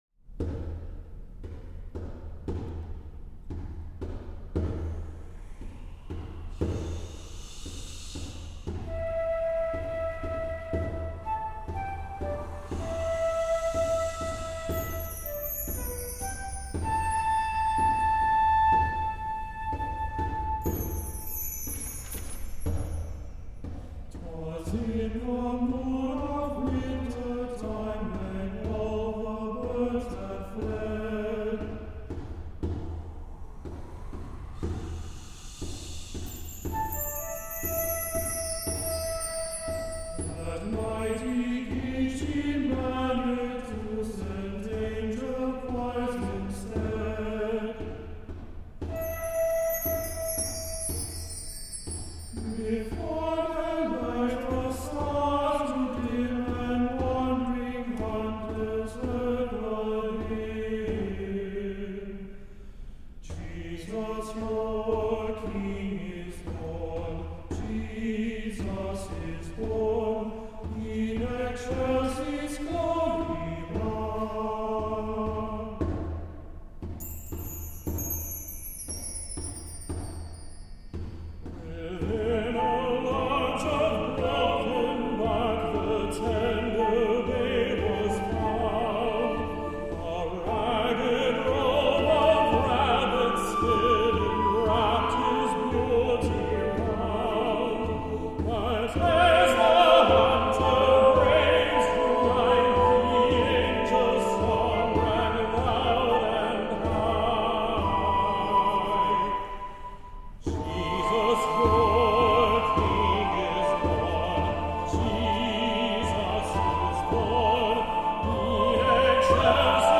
Voicing: SATB/inst.